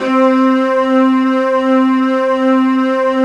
12 STRING C3.wav